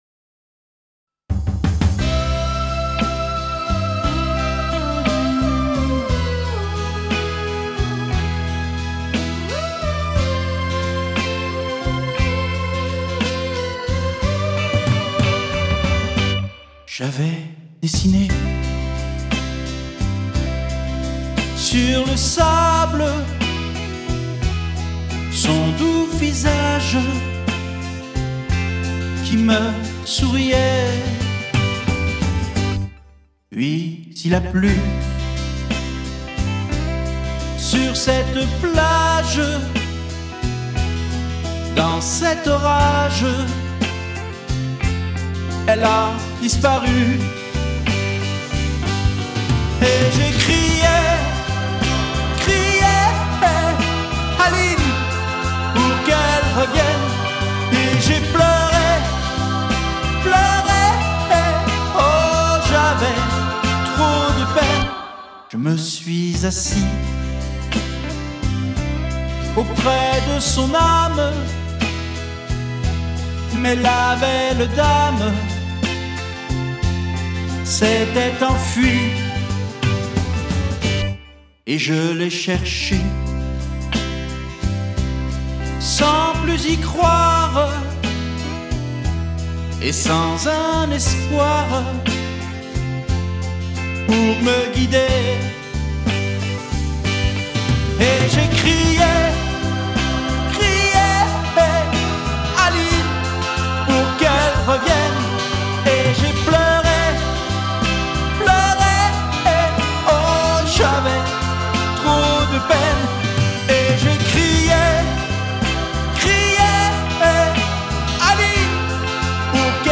La version chantée